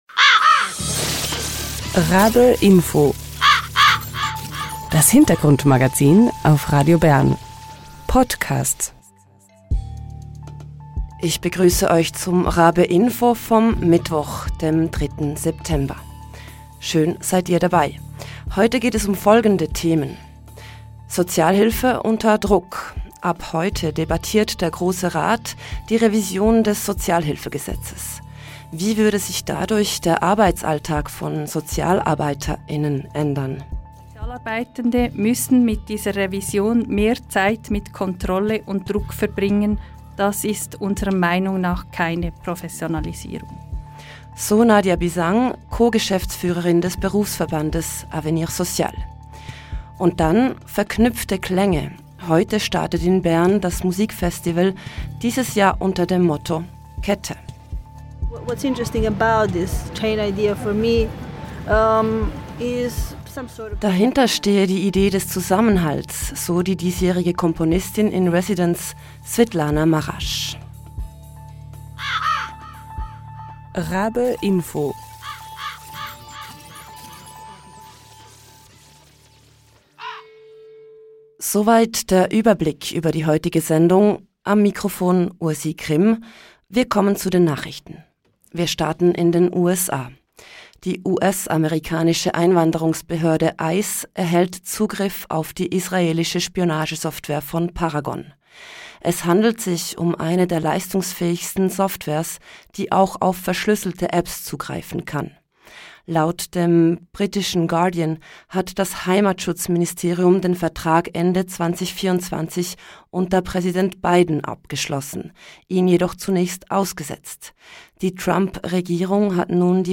Im Interview spricht sie über ihre Musik und Installationen.